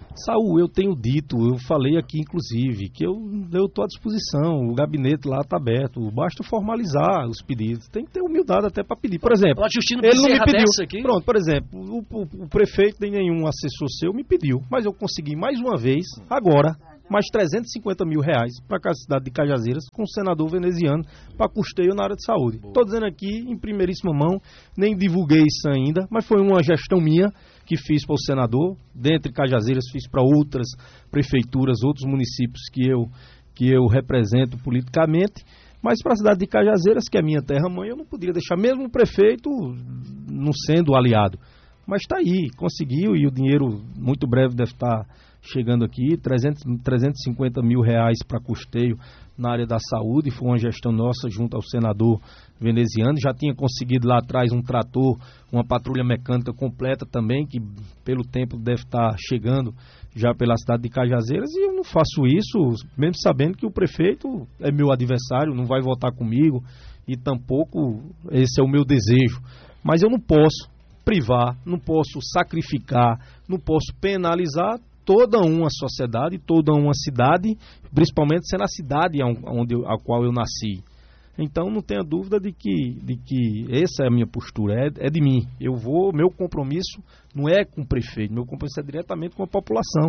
Em entrevista ao programa Rádio Vivo da Alto Piranhas, o deputado estadual Júnior Araújo (Avante), confirmou que fez gestão junto ao senador Veneziano Vital (PSB) para Cajazeiras e outras cidades onde o deputado tem atuação parlamentar.
OUÇA: Deputado Estadual Júnior Araújo (Avante)